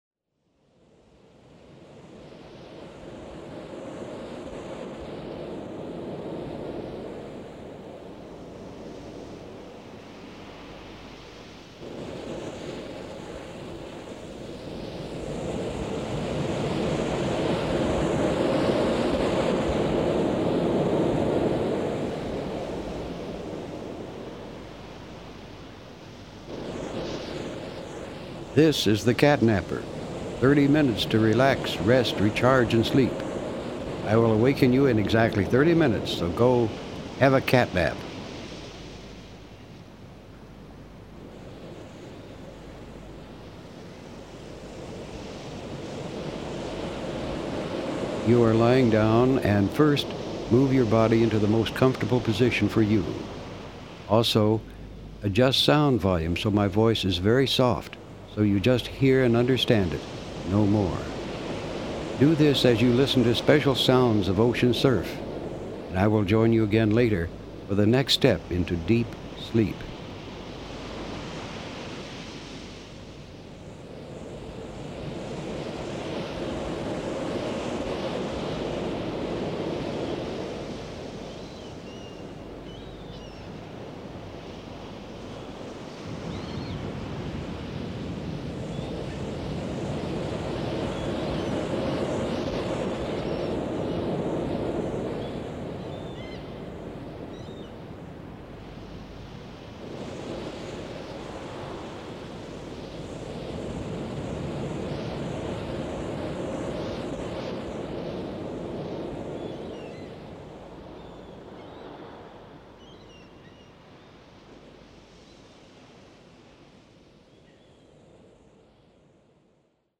Verbal guidance and Hemi-Sync® provide you with a unique opportunity to obtain deeply restorative rest.
A Hemi-Sync® classic voiced by Robert Monroe. 32 min.